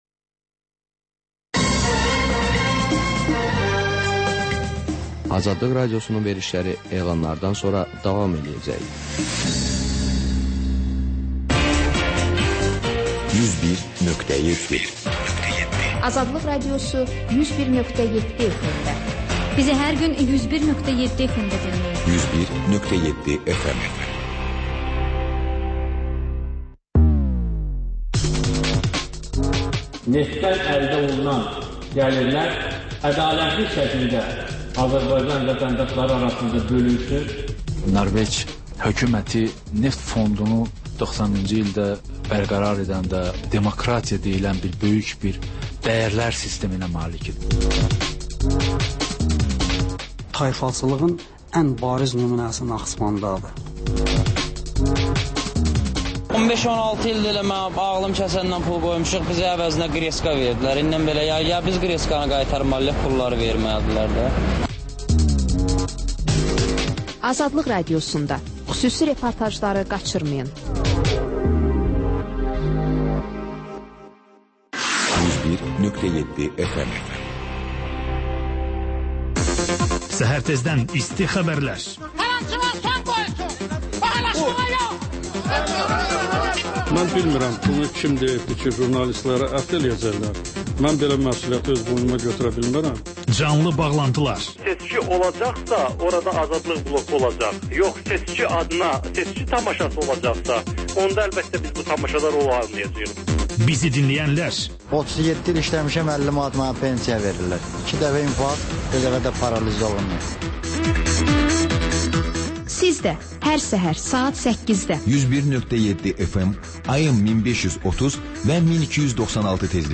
Xəbərlər, sonra CAN BAKI: Bakının ictimai və mədəni yaşamı, düşüncə və əyləncə həyatı…